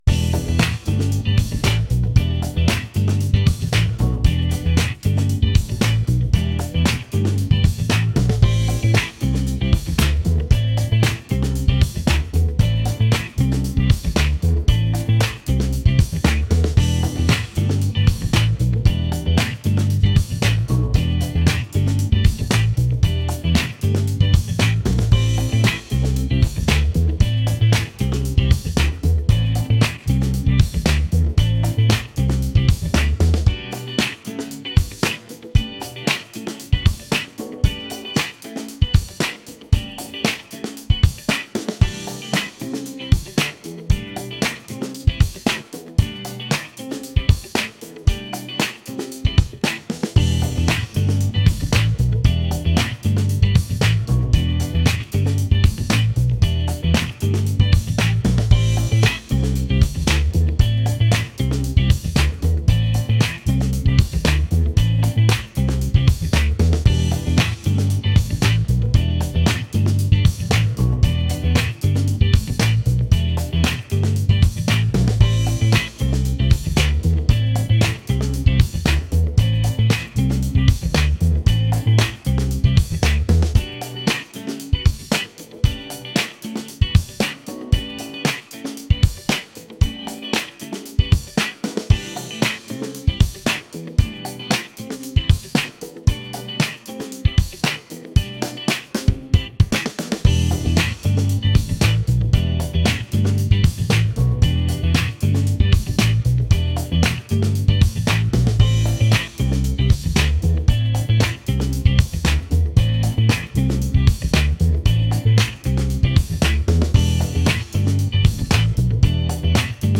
funky | soul | upbeat